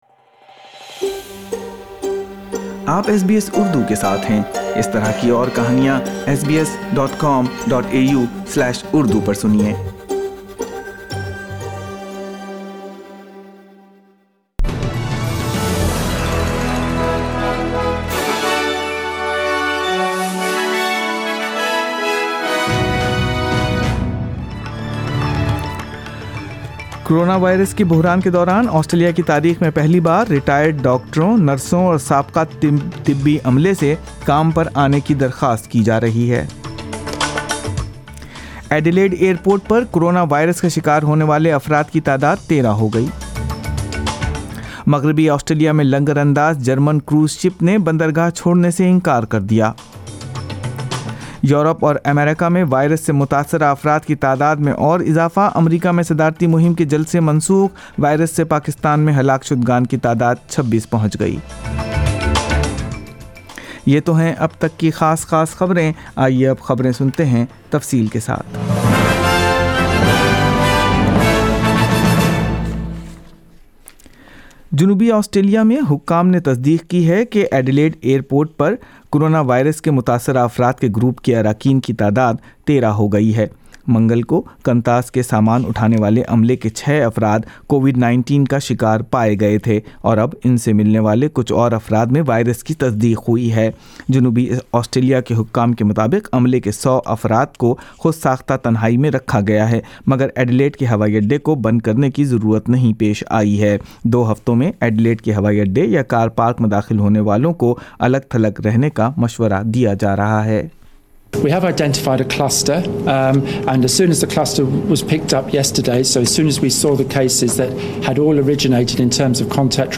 ایس بی ایس اردو خبریں ۲ اپریل ۲۰۲۰